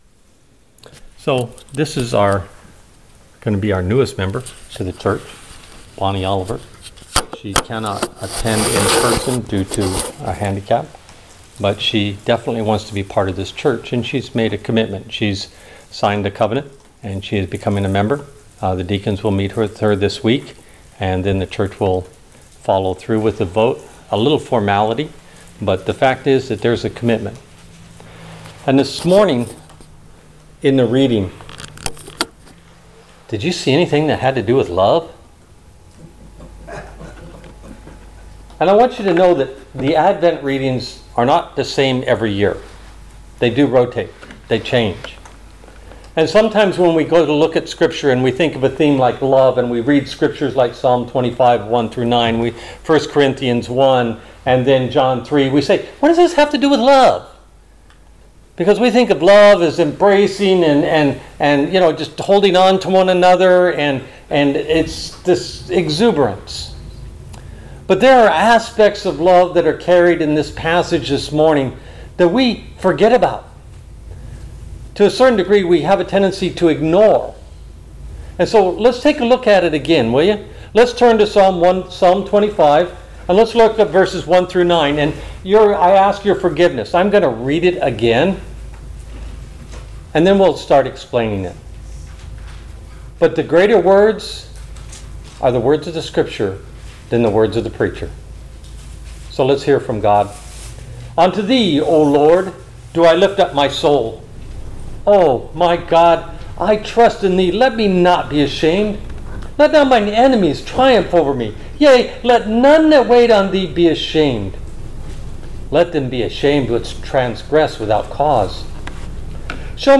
All Sermons The Advent of Love 19 December 2021 Series